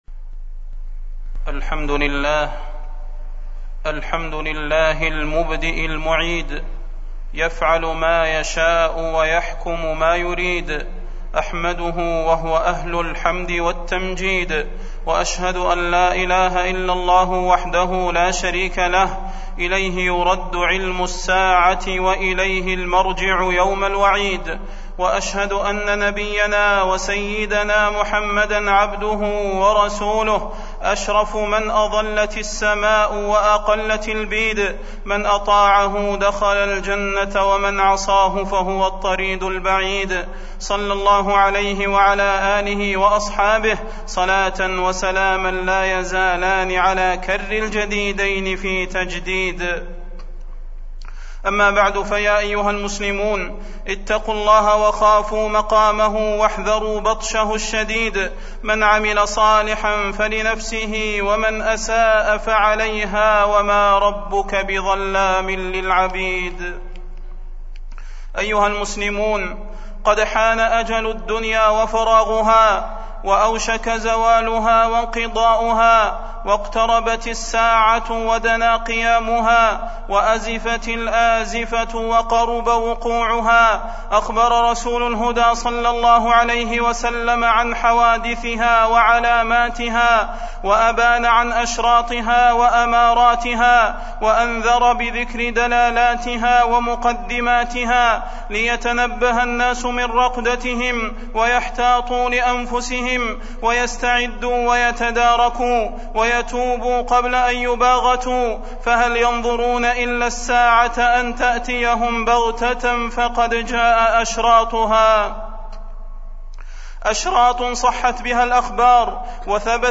تاريخ النشر ٣٠ شوال ١٤٢٦ هـ المكان: المسجد النبوي الشيخ: فضيلة الشيخ د. صلاح بن محمد البدير فضيلة الشيخ د. صلاح بن محمد البدير أشراط الساعة